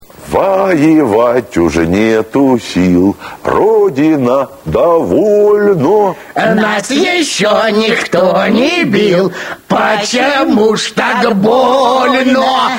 levsong.mp3